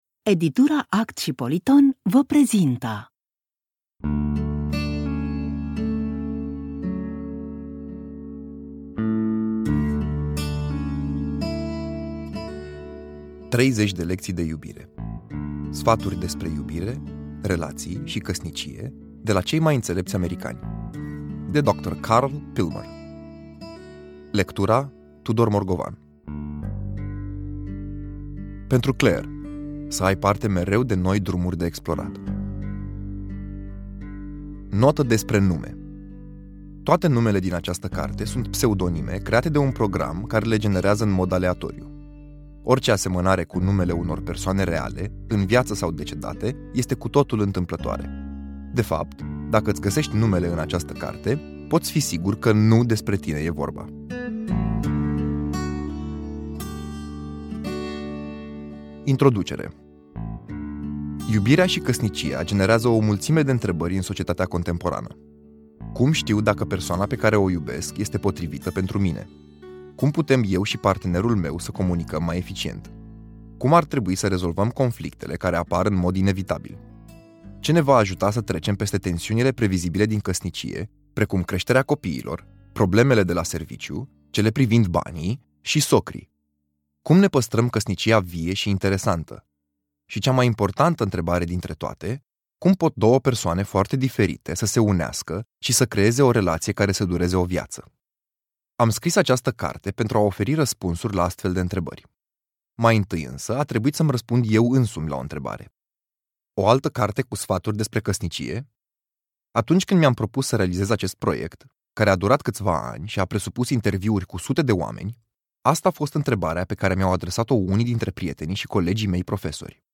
ASCULTAȚI GRATUIT UN FRAGMENT
mono Poate fi descarcat pe orice dispozitiv